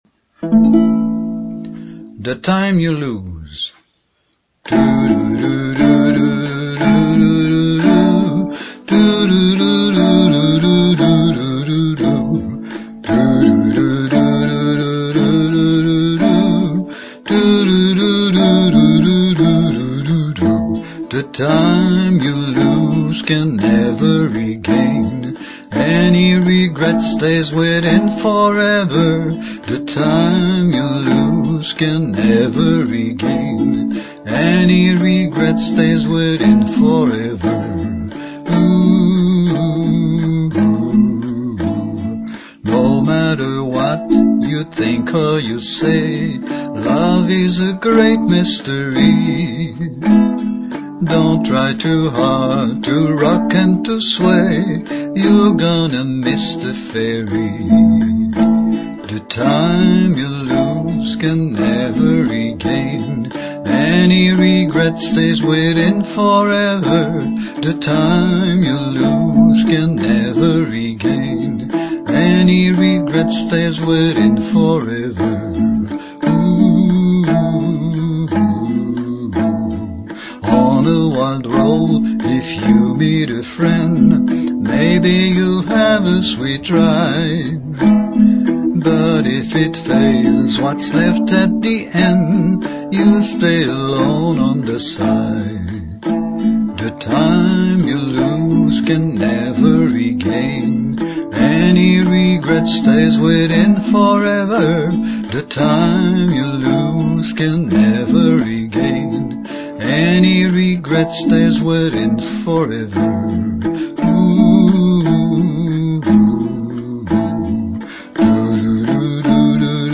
thetimeyouloseuke.mp3
Here is one of my songs.Rough recording: My voice + ukulele
Intro: 16 bars (key of A, 3/4) The time you lose can never regain Any regret stays within forever The time you lose can never regain Any regret stays within forever ...